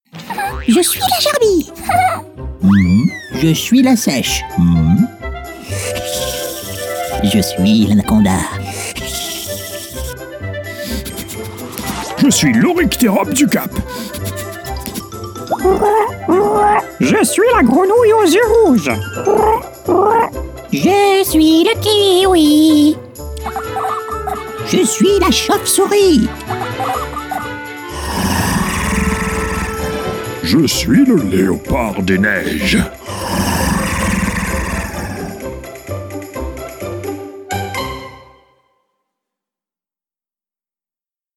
Sprechprobe: Sonstiges (Muttersprache):
Animal sound.mp3